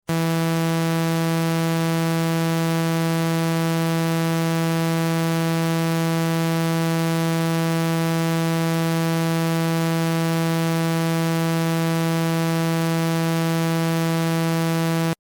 Klang 3, Kategorie: Dreieckschwingung (Triangle)
Monophone Klänge:
TRI-Dreieck.mp3